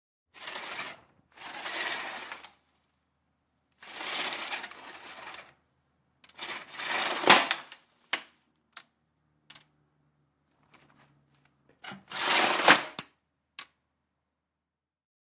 DOORS/STRUCTURES WINDOWS: Roller blinds, x4.